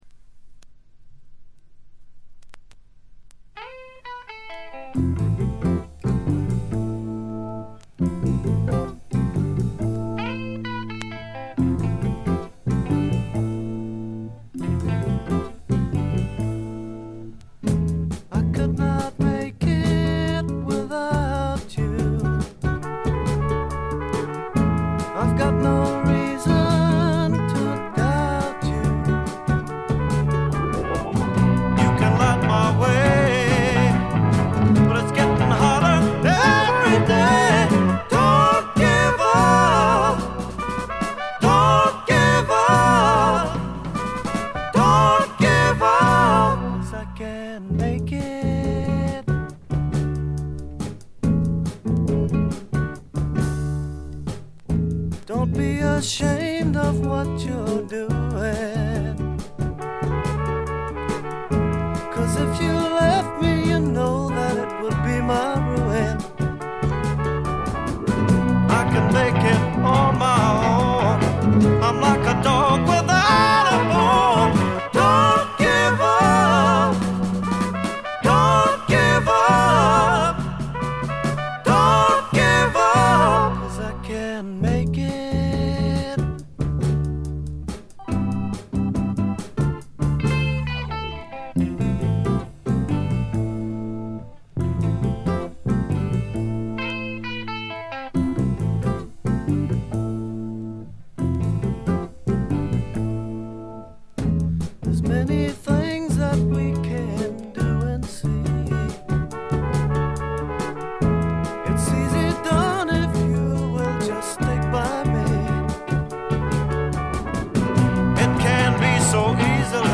静音部でのわずかなノイズ感程度。
試聴曲は現品からの取り込み音源です。